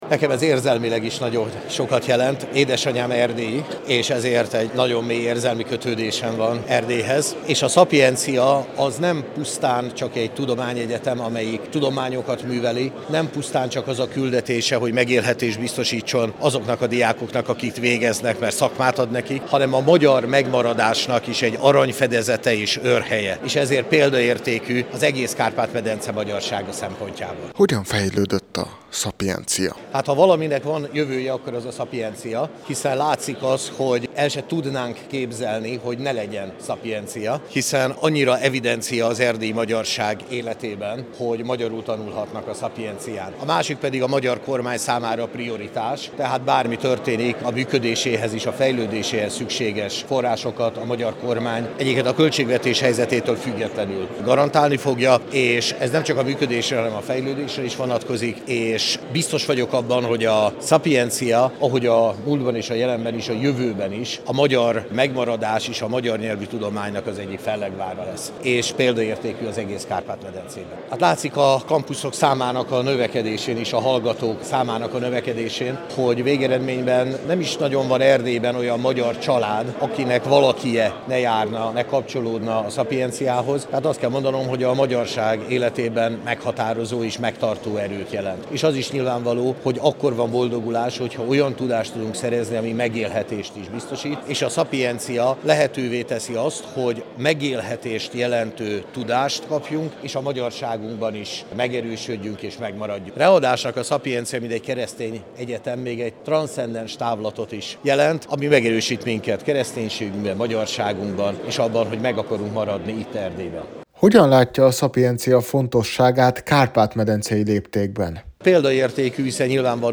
Az immáron hagyományosnak számító ünnepséget idén október 7-én, szombaton tartották.
A Sapientia nemcsak egy tudományegyetem, amelyik szakmát ad az ott végzetteknek, hanem a magyar megmaradás őrhelye is Erdélyben, amelyet minden körülmények között támogatni fog Magyarország Kormánya, tett ígéretet Semjén Zsolt, Magyarország miniszterelnök-helyettese, aki az idei évben vehette át a Bocskai-díjat.